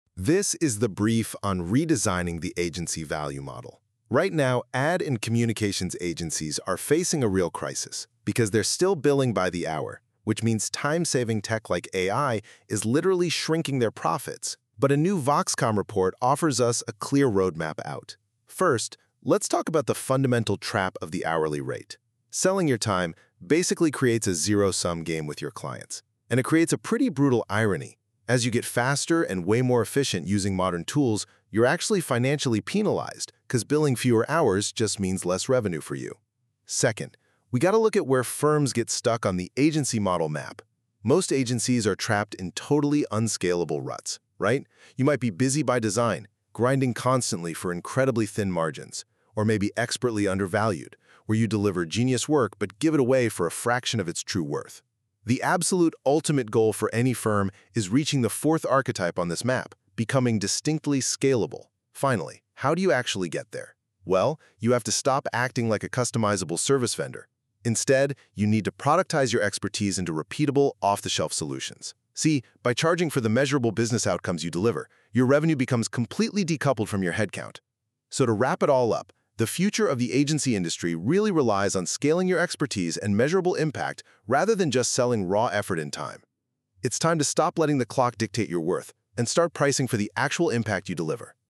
I also asked the agent to generate a brief audio overview (click below) and a " mindmap " delineating the thinking of the report, which you can see here.